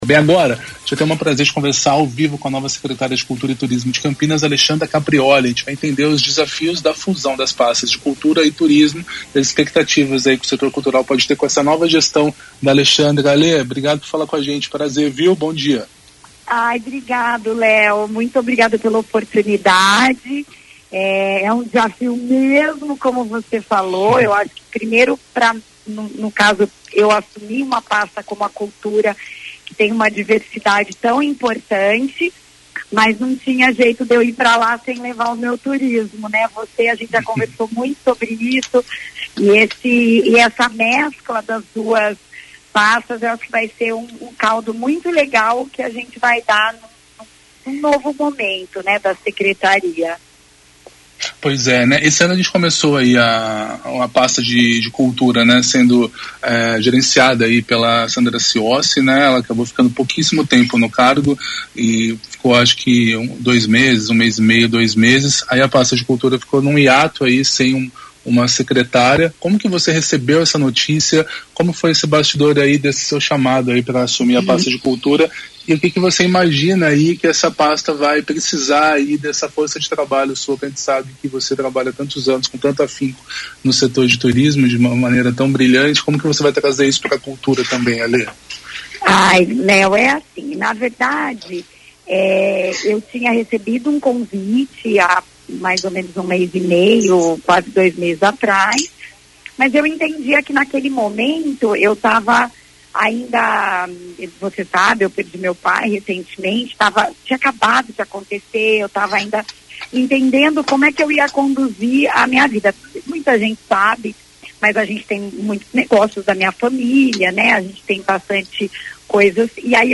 O CBN Cultura conversou ao vivo com a nova Secretária de Cultura e Turismo de Campinas, Alexandra Caprioli. Os desafios da fusão das pastas e as expectativas que o setor cultural pode ter com a nova gestão foram alguns dos temas. Alexandra ainda respondeu perguntas dos ouvintes e anunciou nomes que irão compor a secretaria.